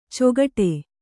♪ cogaṭe